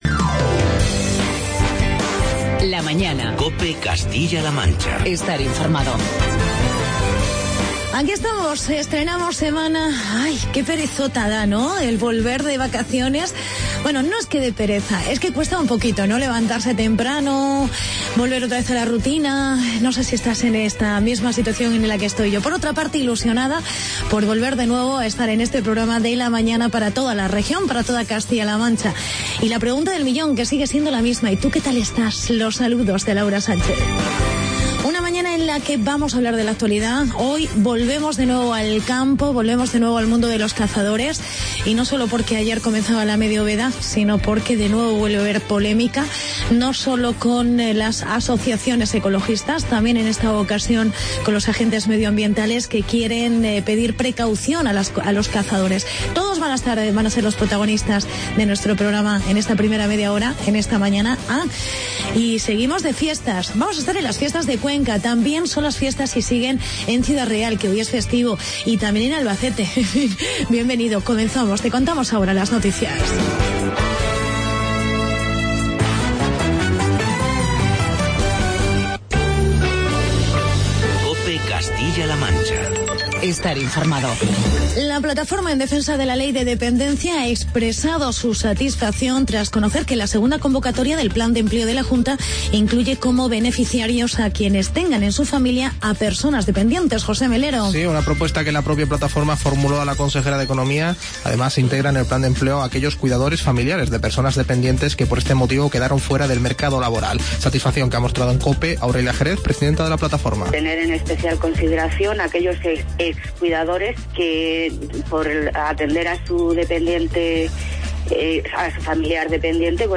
Entrevista con el concejal de festejos de Cuenca, Pedro José García Hidalgo con quien hablamos de la Ferias y Fiestas en honor a San Julián.